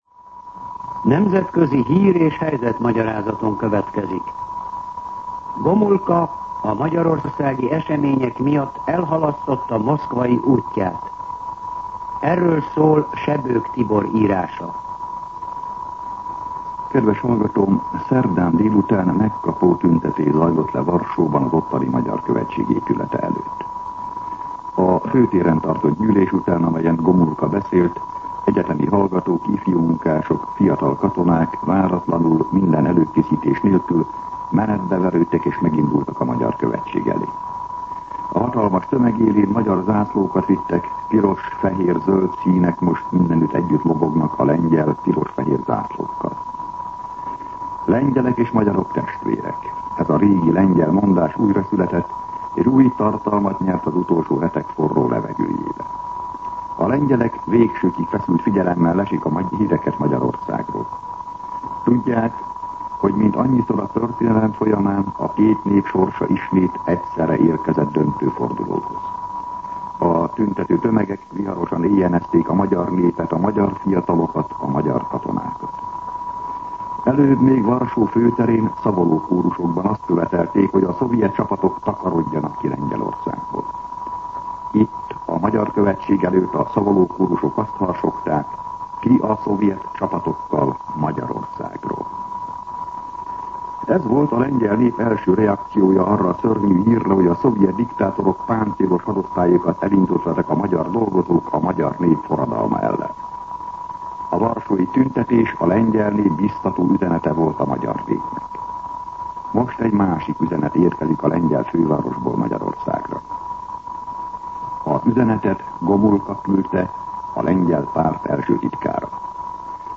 Nemzetközi hír- és helyzetmagyarázat